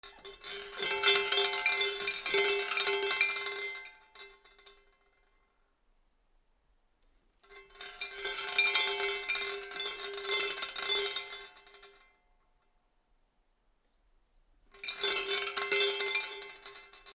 / G｜音を出すもの / G-10 和_寺社仏閣
神社の鈴(坪鈴)
お賽銭箱の前のガラガラMKH816